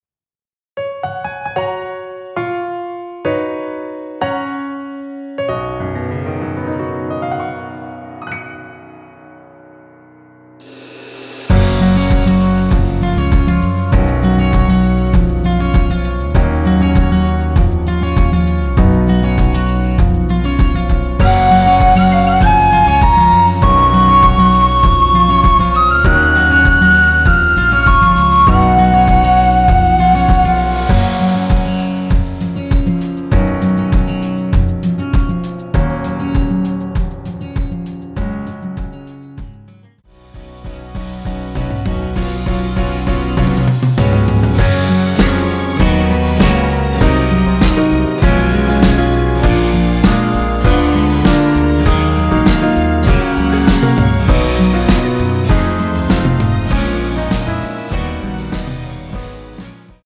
원키에서(-2)내린 MR입니다.
F#
앞부분30초, 뒷부분30초씩 편집해서 올려 드리고 있습니다.